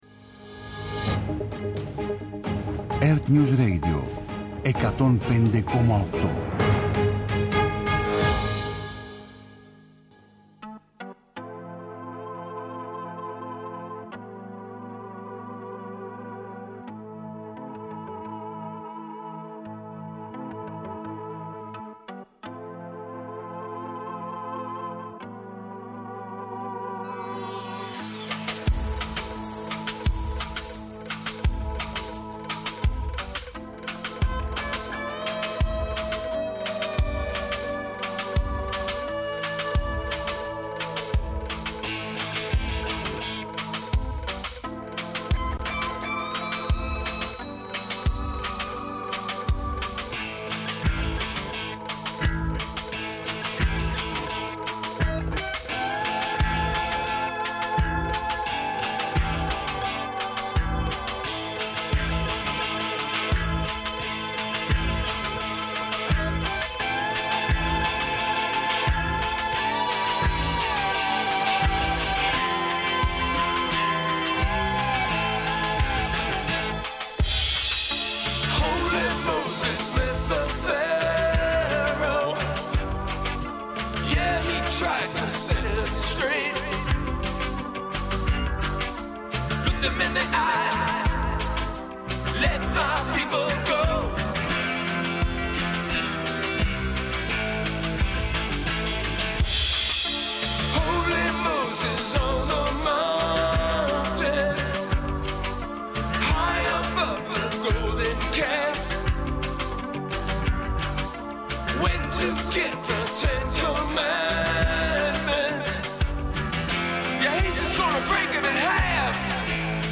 Νυχτερινές ιστορίες με μουσικές και τραγούδια που έγραψαν ιστορία.